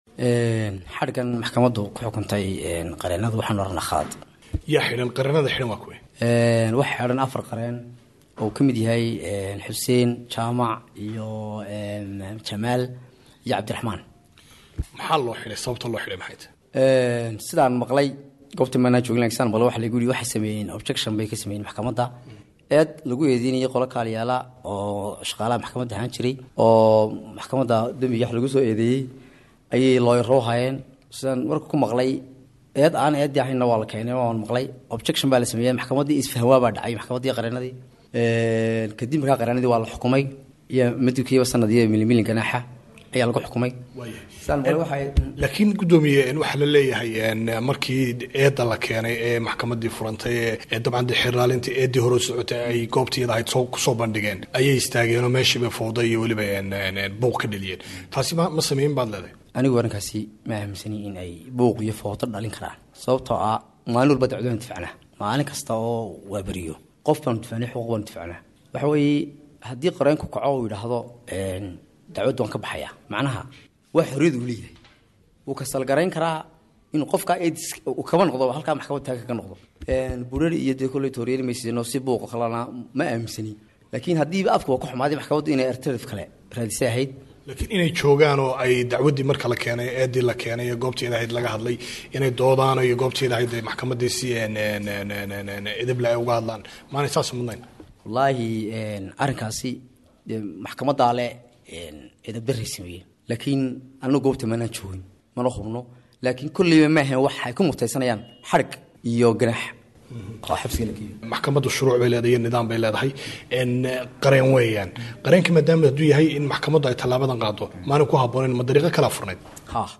Wareysi: Guddoomiyaha Maxkamadda Hargeysa